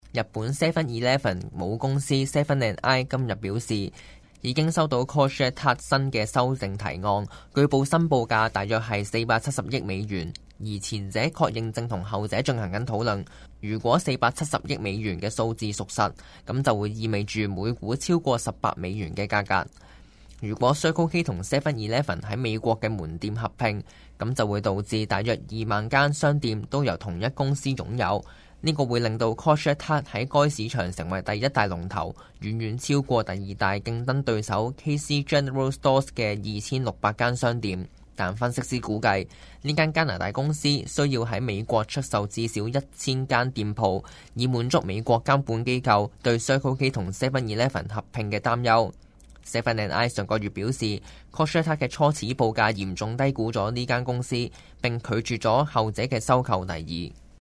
Canada/World News 全國/世界新聞